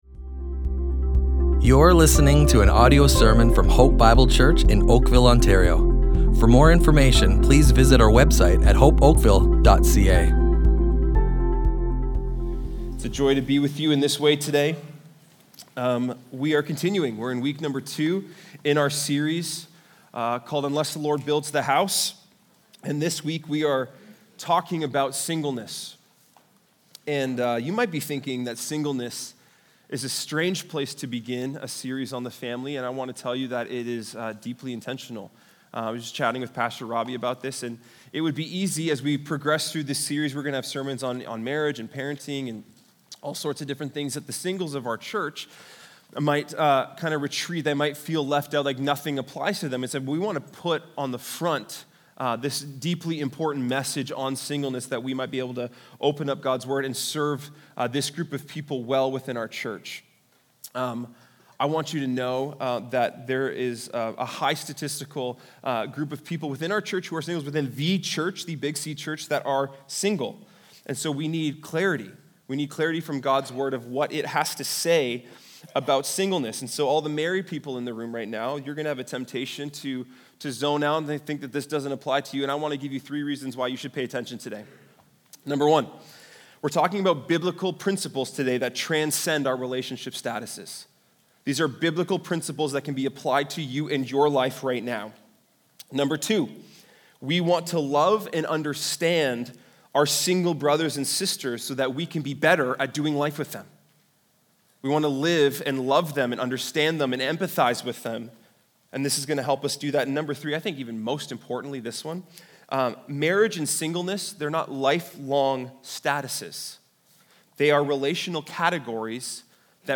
Hope Bible Church Oakville Audio Sermons